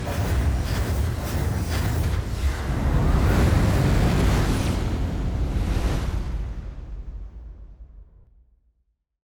jumpend.wav